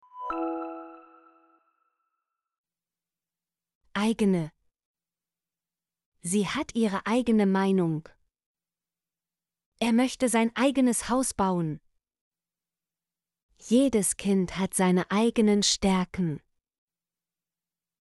eigene - Example Sentences & Pronunciation, German Frequency List